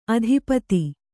♪ adhipati